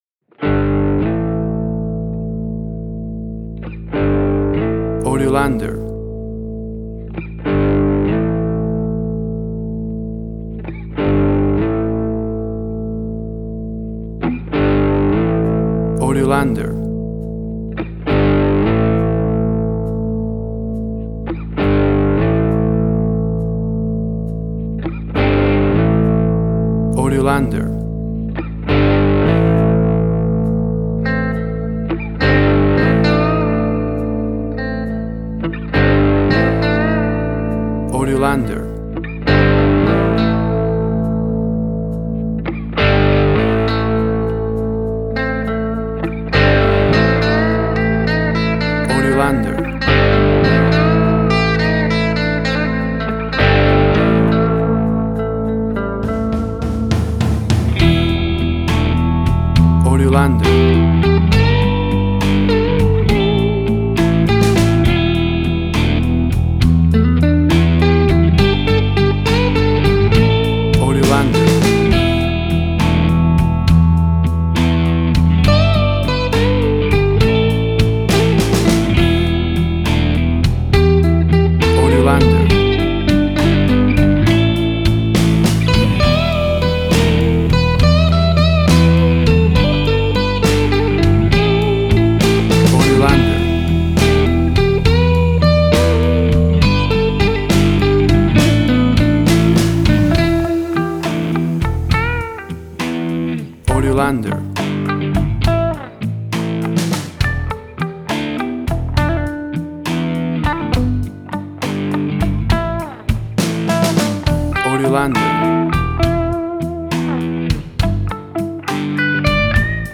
Suspense, Drama, Quirky, Emotional.
Tempo (BPM): 68